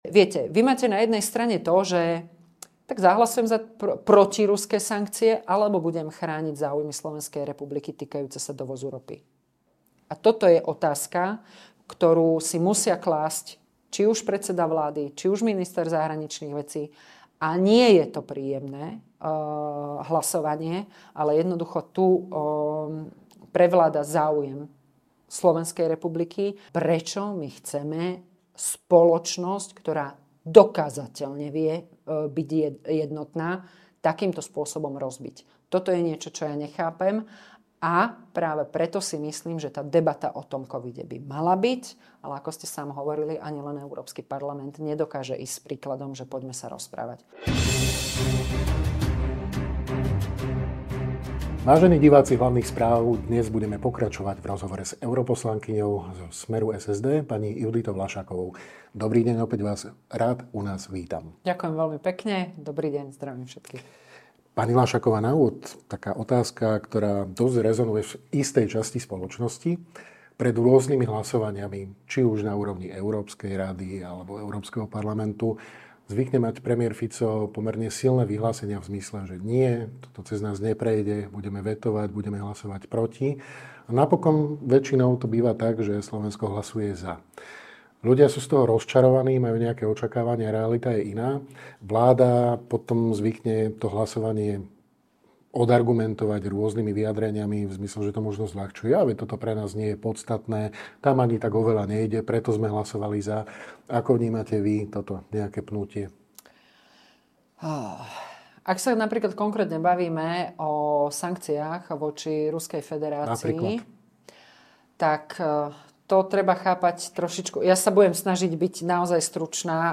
Nedávno sme v štúdiu Hlavných správ privítali europoslankyňu za SMER – SSD, JUDr. Juditu Laššákovú.